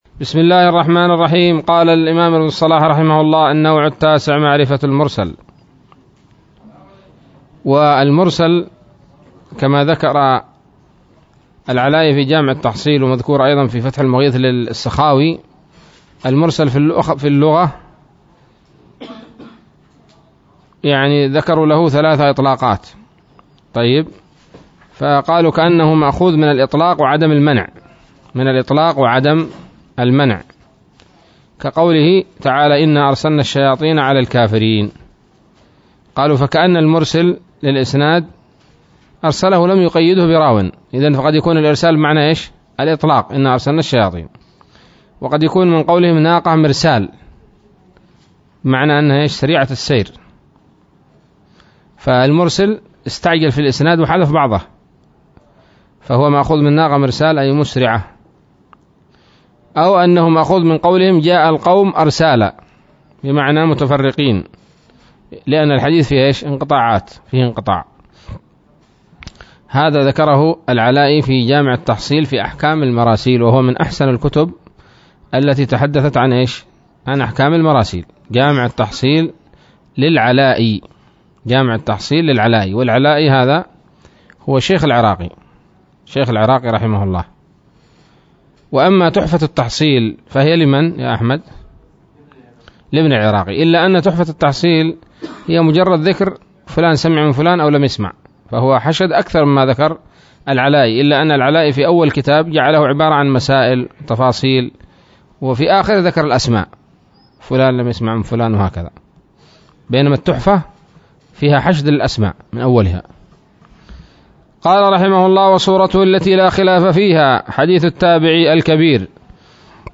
الدرس الثالث والعشرون من مقدمة ابن الصلاح رحمه الله تعالى